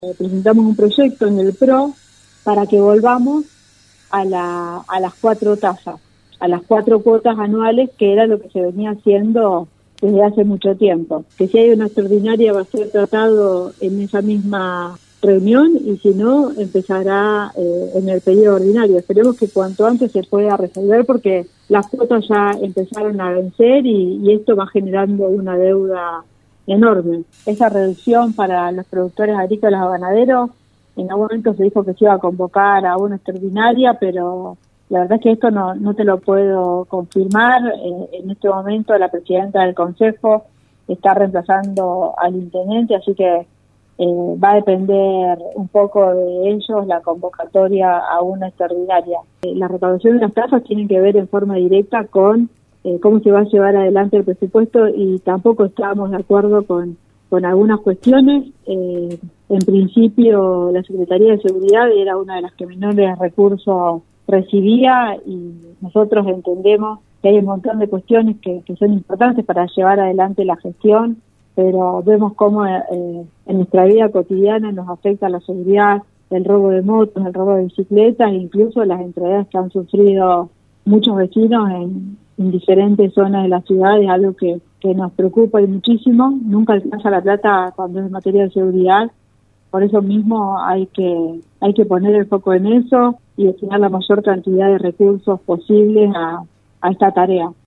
EVANGELINA CABRAL EN RADIO UNIVERSO 93 1